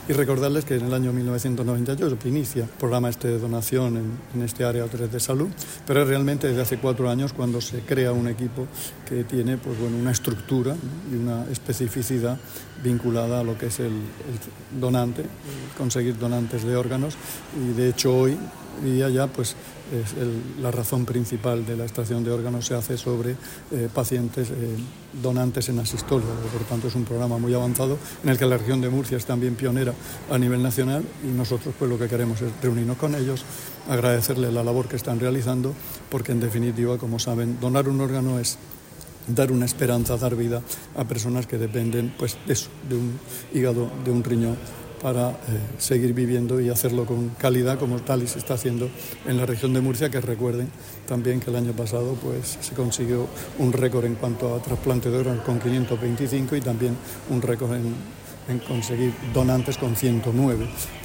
Sonido/ Declaraciones del consejero de Salud, Juan José Pedreño, con motivo del encuentro mantenido con el equipo de donación del hospital de Lorca.